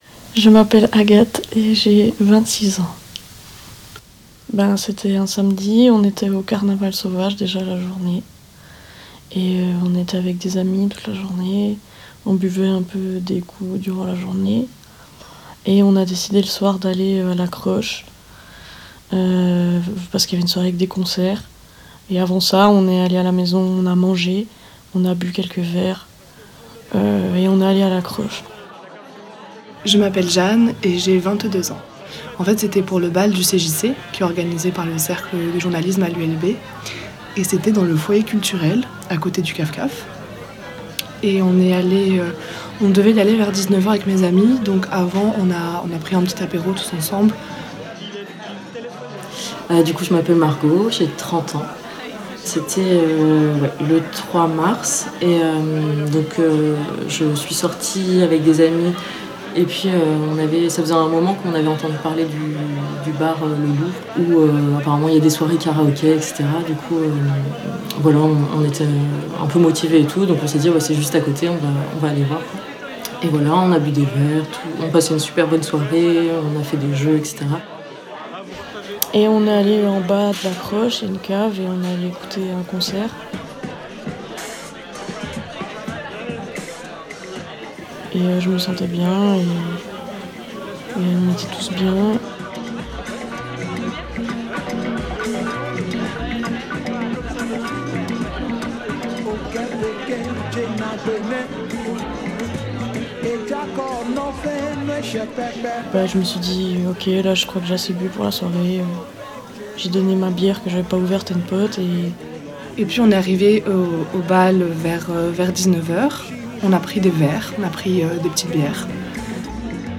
Elles se réveillent avec une amnésie partielle ou totale et un doute sur ce qui s’est réellement passé. Voici les témoignages de trois jeunes femmes à qui on a volé une partie de leur soirée, et de leurs souvenirs. Elles partagent ici leur expérience, le travail nécessaire pour reconstituer le déroulement de leur soirée et la difficulté d’obtenir une reconnaissance de ce qui leur est arrivé.